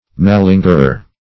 Malingerer \Ma*lin"ger*er\, n. [F. malingre sickly, weakly,